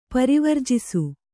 ♪ parivarjisu